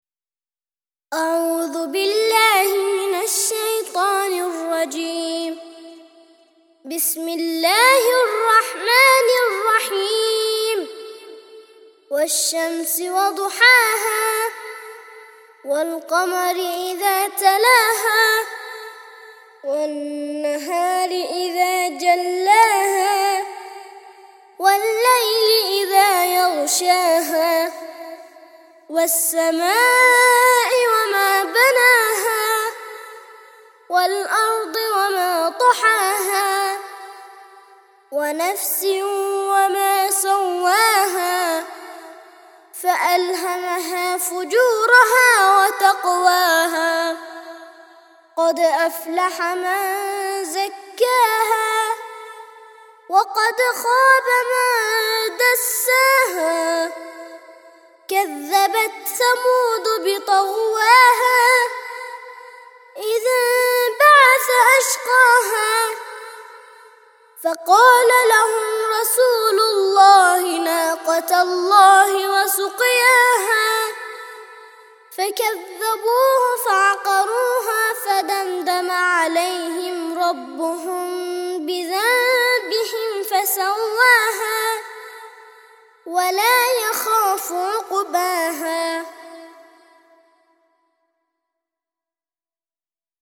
91- سورة الشمس - ترتيل سورة الشمس للأطفال لحفظ الملف في مجلد خاص اضغط بالزر الأيمن هنا ثم اختر (حفظ الهدف باسم - Save Target As) واختر المكان المناسب